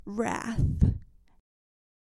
描述：女性，口语，七宗罪
Tag: 7 致命的 口语